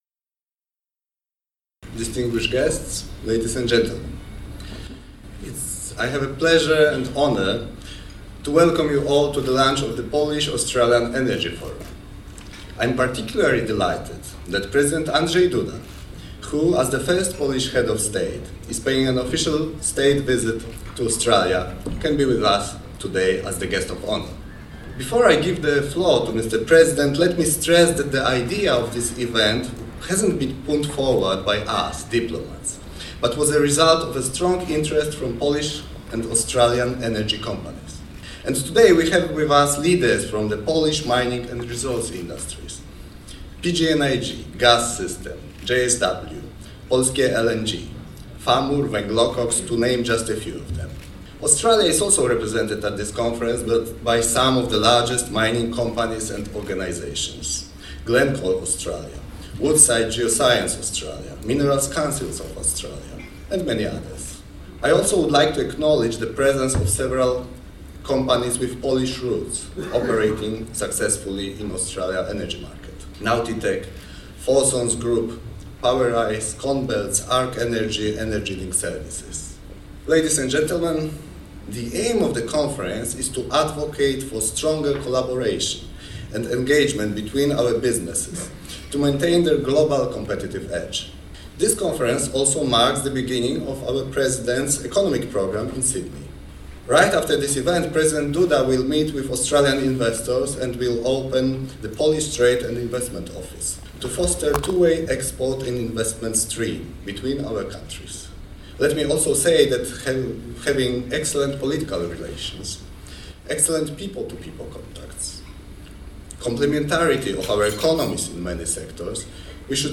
Polish Australian Energy Forum in Sydney 21st August 2018
A oto nagrania Pulsu Polonii - dwa przemówienia w języku angielskim.
Listen to a short speech by H.E. Ambassador M.Kolodziejski